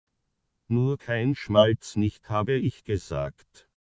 und im Schönbrunner Deutsch: